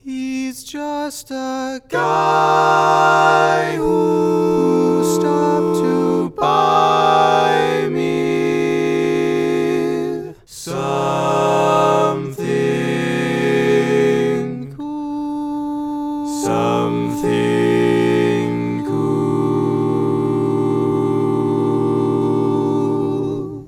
Key written in: E♭ Major
How many parts: 4
Type: SATB
All Parts mix: